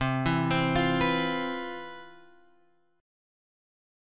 C Major 11th